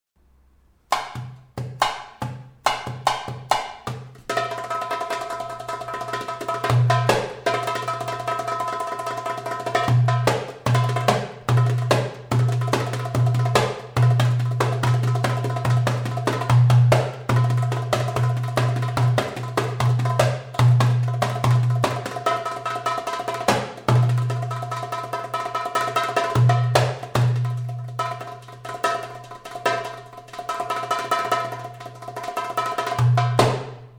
רול מטורף על הדרבוקה